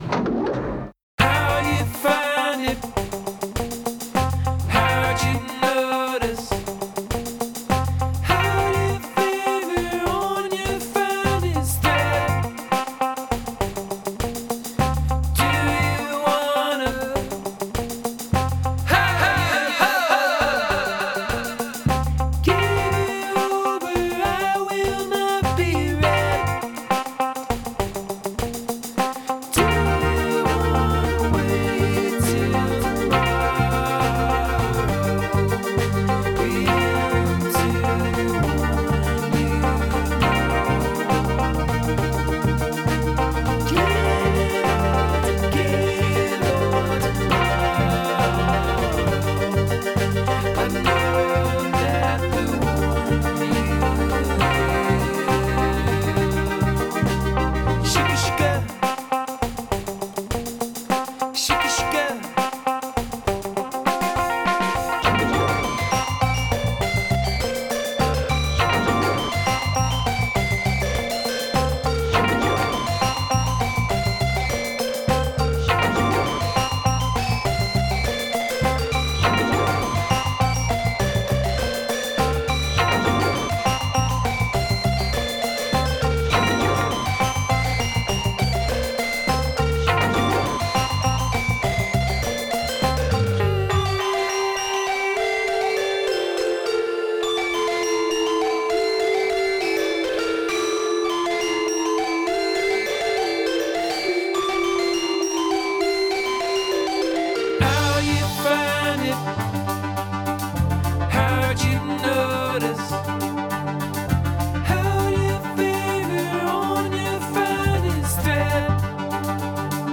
features a funkier vibe than most anything in UPS’ catalog.
this funky, polyrhythmic material?